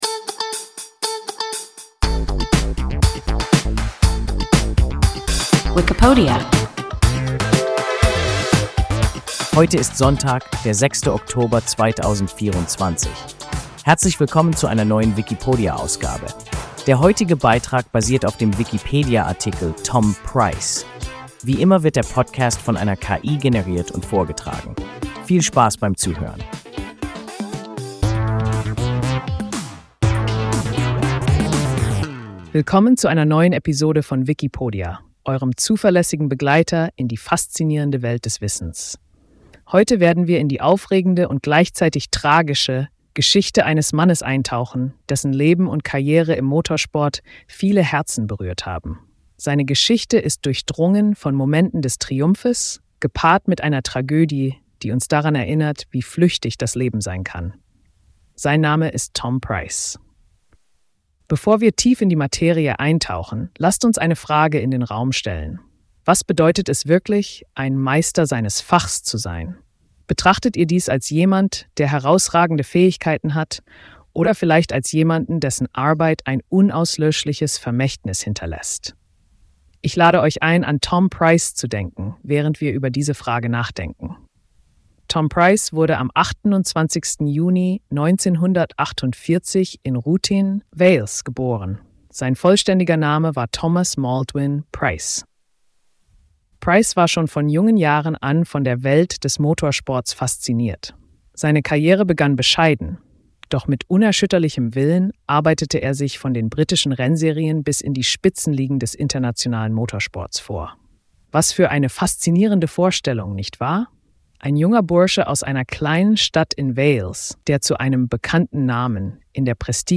Tom Pryce – WIKIPODIA – ein KI Podcast